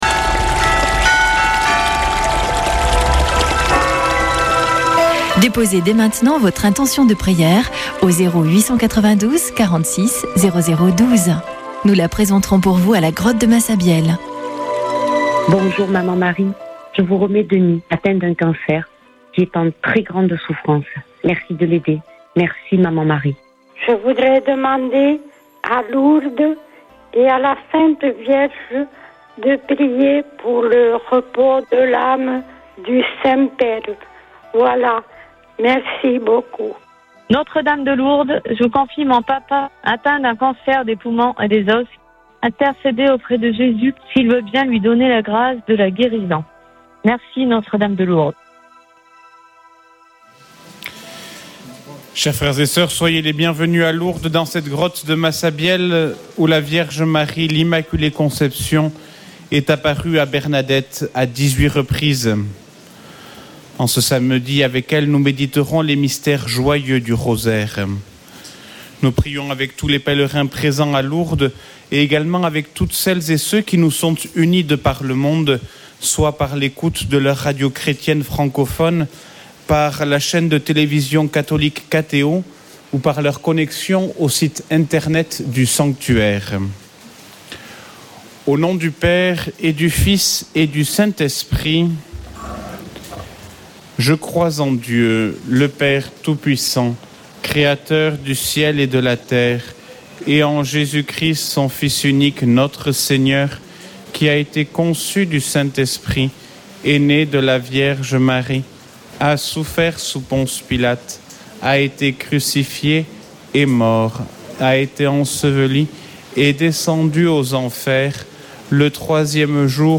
Chapelet de Lourdes du 26 avr.
Une émission présentée par Chapelains de Lourdes